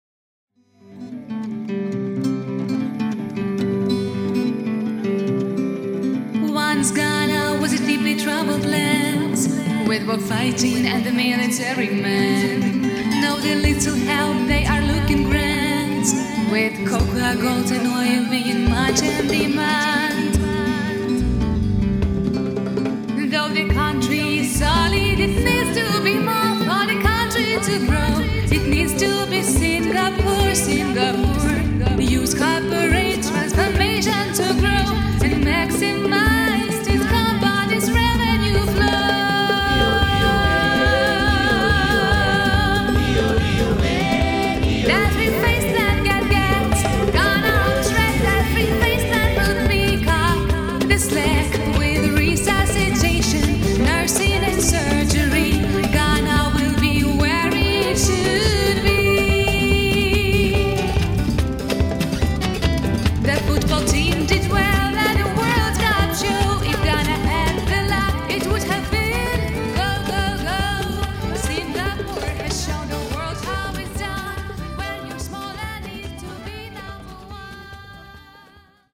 Африканская песня Категория: Написание музыки